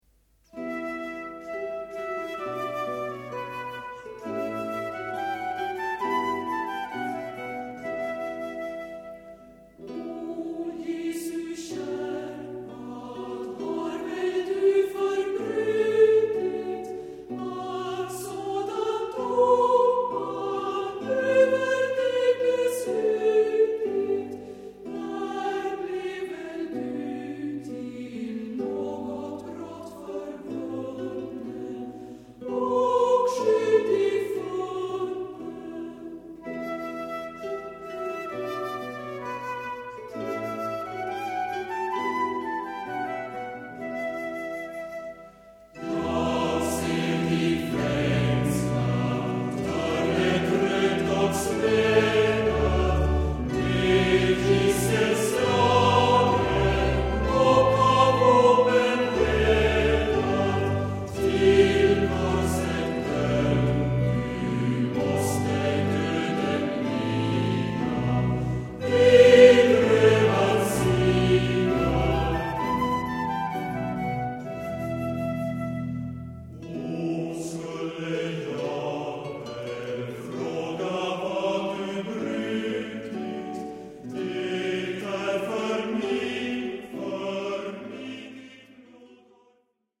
★ 安定祥和、莊嚴聖潔的人聲合唱讓您暫離塵囂，百聽不厭！
★ 北國瑞典天使歌聲，獨特純淨空靈意境、音色柔軟綿密、通透清晰！